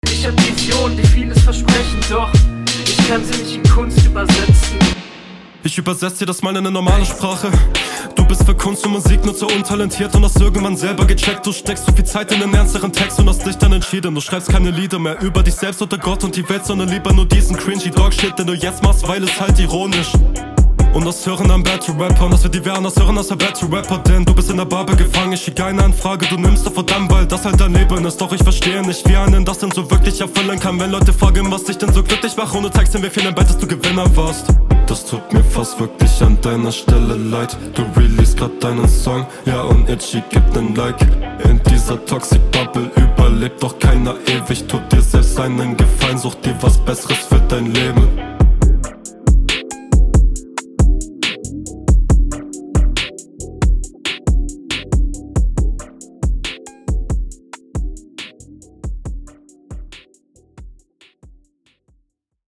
ok ok runde 3 lets go direkt songausschnitt na geht doch, guter flow, jetzt gehts …
Flowlich sehr cool.